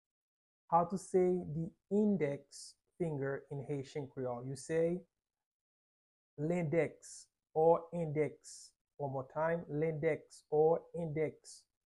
How to say "Index Finger" in Haitian Creole - "Lendèks" pronunciation by a private Haitian tutor
“Lendèks” Pronunciation in Haitian Creole by a native Haitian can be heard in the audio here or in the video below: